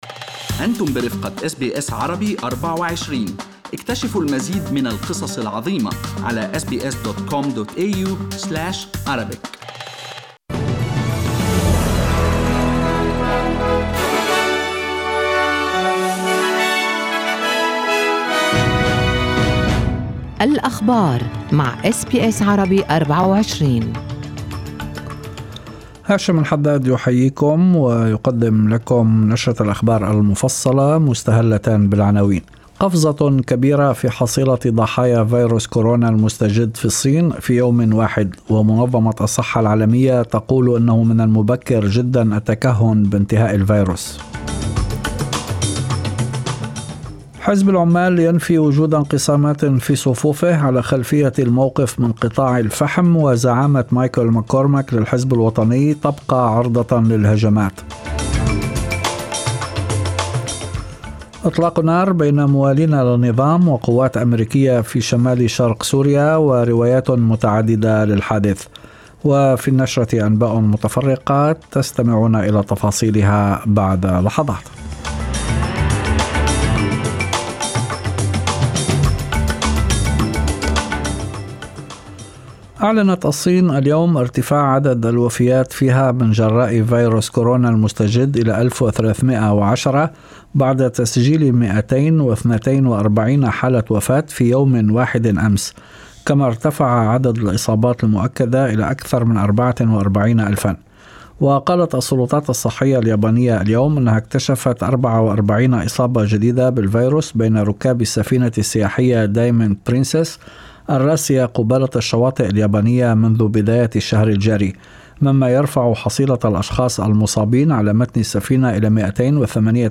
نشرة أخبار المساء 13/02/2020
Arabic News Bulletin Source: SBS Arabic24